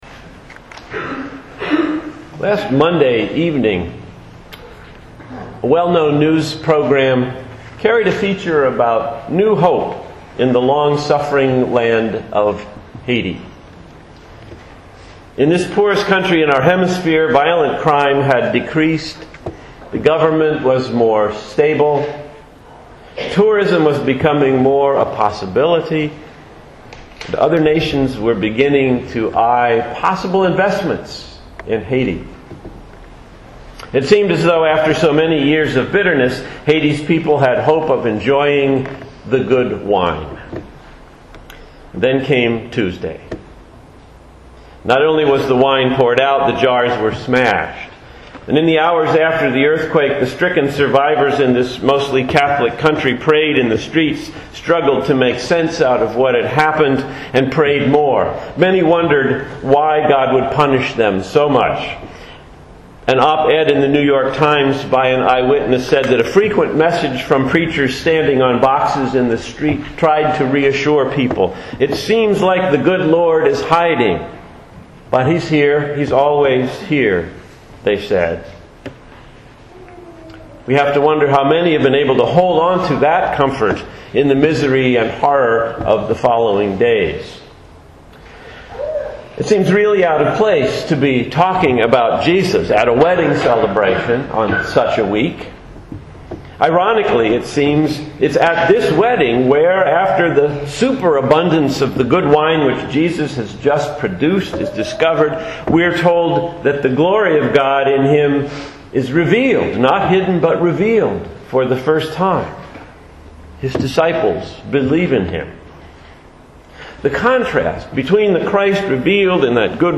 Sermon: The good wine
Here is the sermon from Burlington Presbyterian Church for January 17, 2010 called “The Good Wine”.